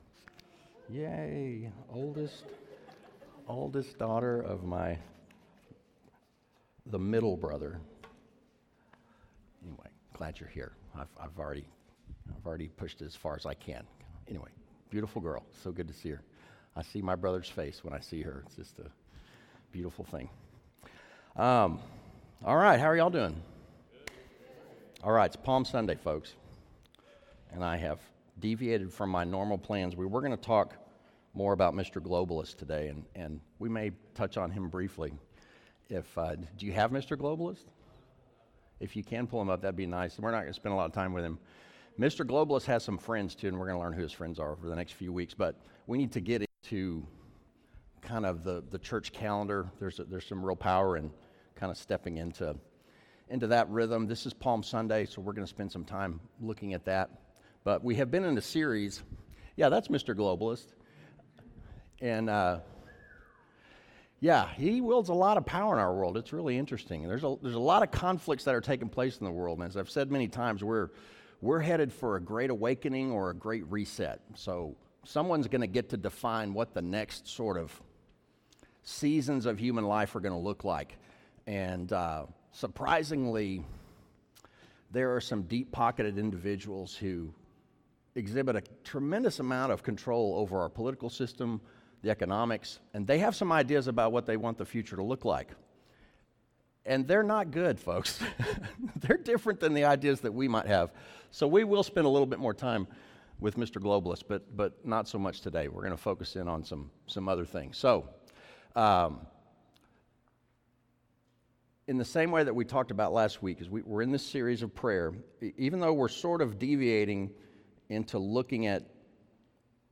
The mic cut auto before the sermon was done.